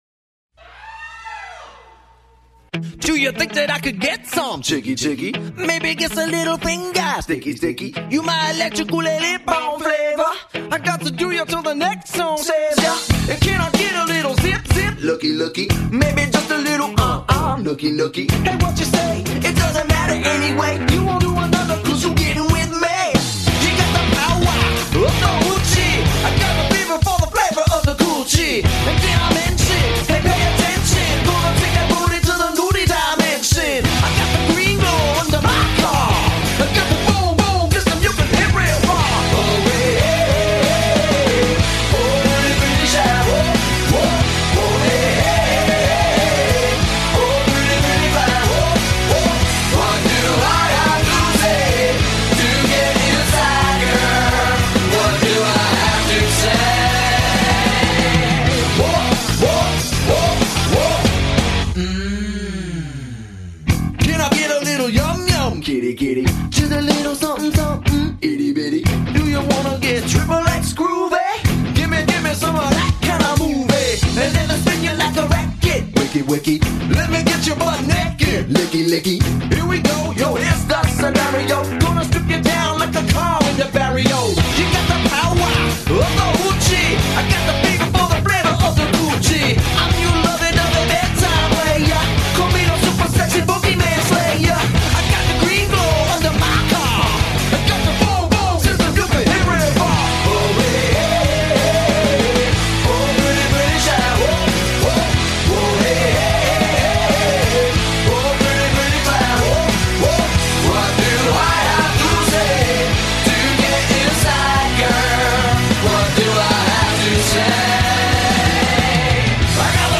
Genre: Alt.Rock.